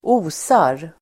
Ladda ner uttalet
Uttal: [²'o:sar]